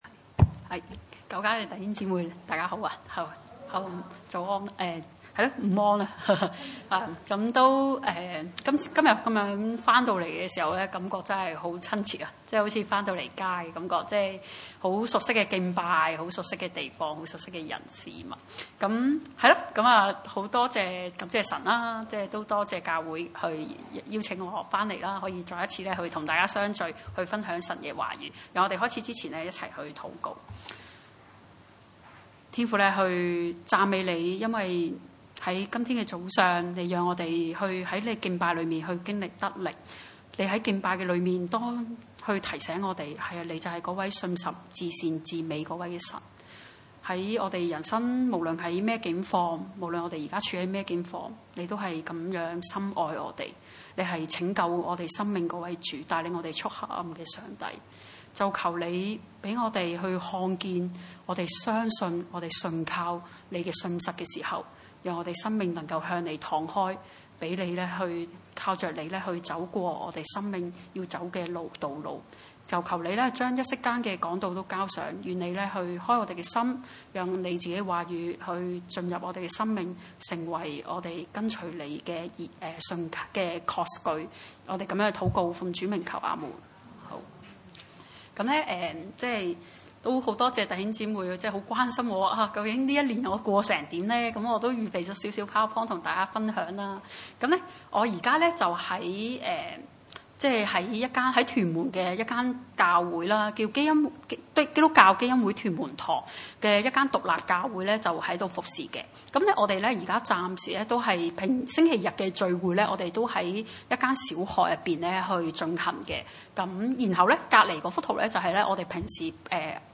1-21 崇拜類別: 主日午堂崇拜 1 童子撒母耳在以利面前事奉耶和華。